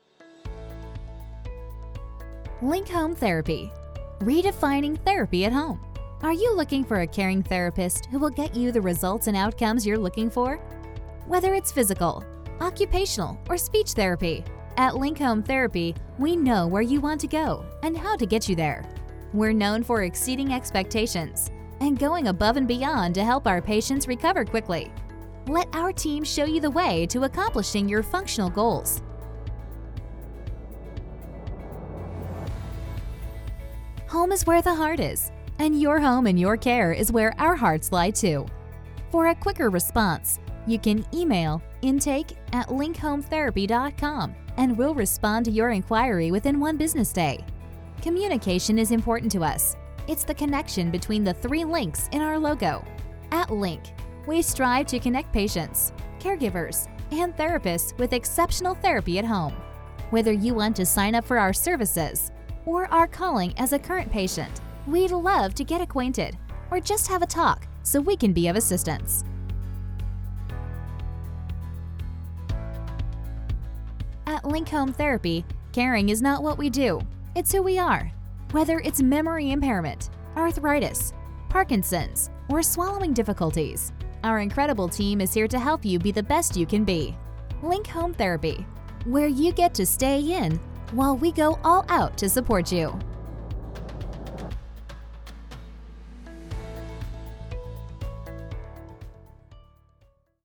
Male
Link-On-Hold-mp3cut.net_.mp3